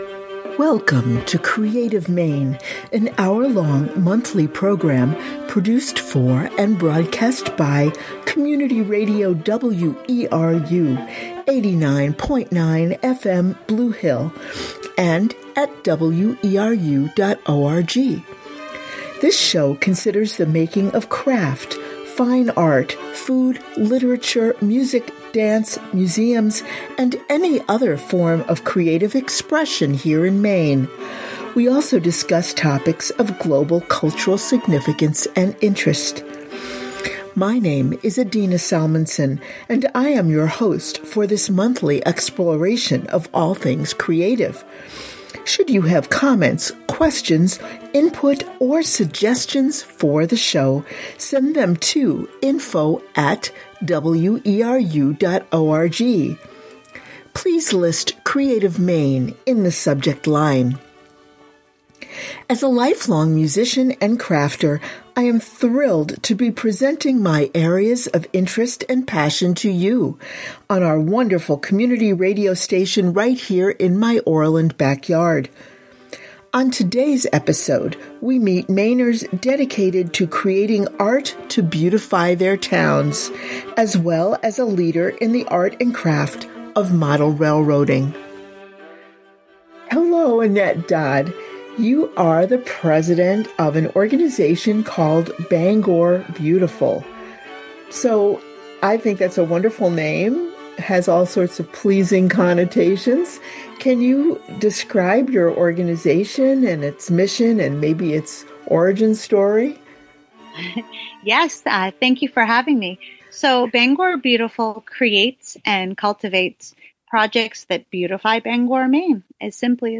MP3 Audio Archive Recordings (aka Podcasts) of all locally produced spoken word programming from Community Radio WERU 89.9 FM Blue Hill, Maine - Part 40